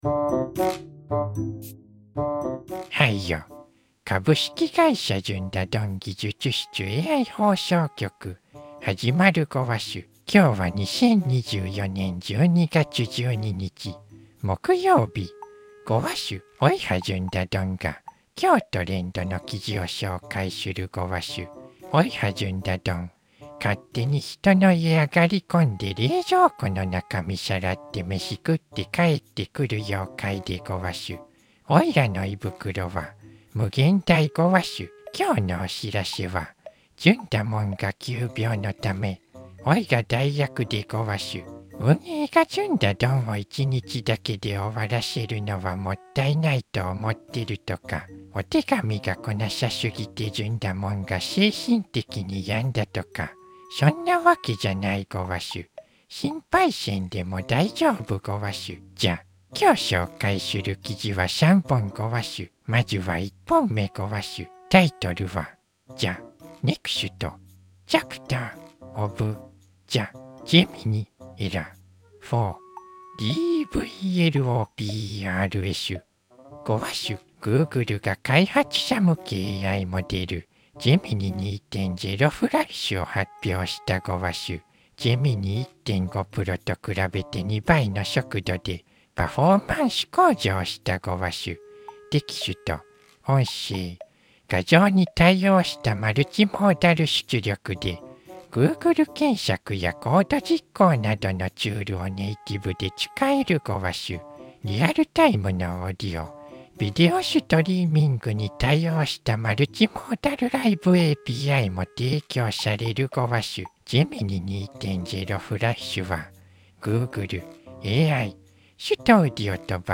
ずんだもんが急病のため急遽代役で、おいはずんだどんごわす。
ずんだどん